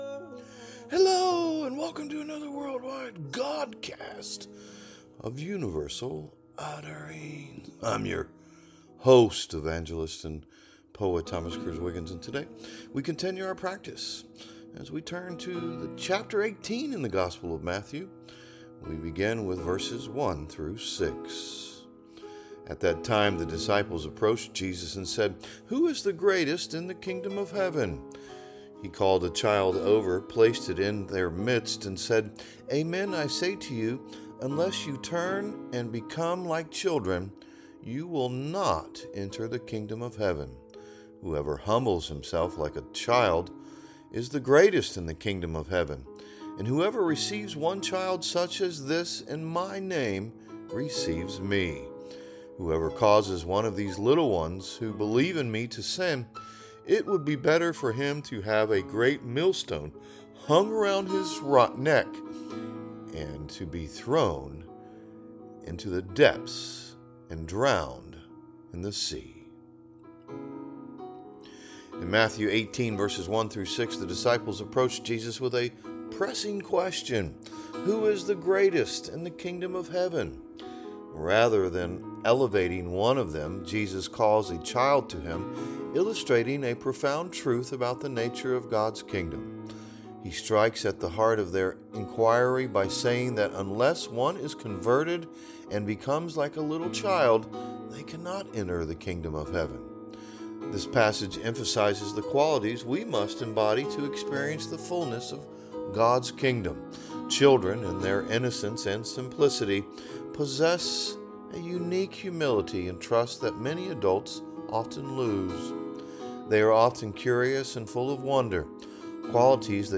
A Godcast